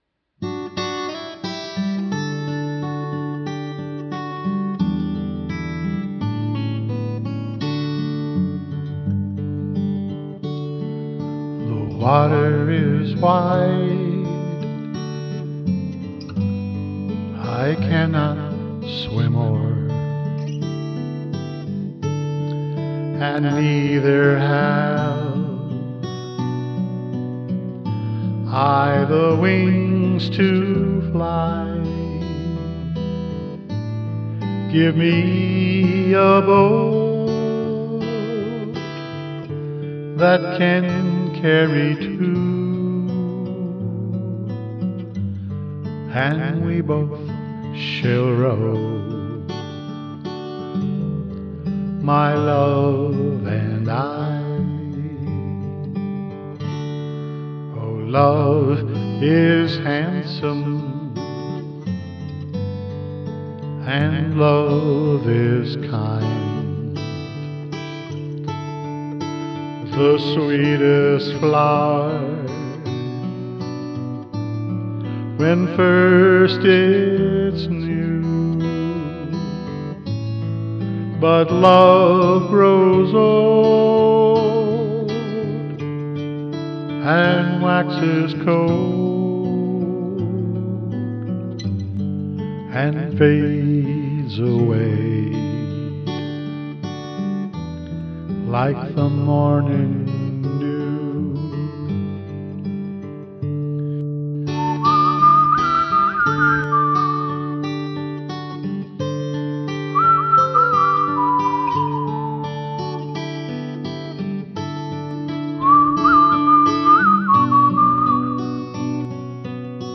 The Water Is Wide (an old Scottish folk song)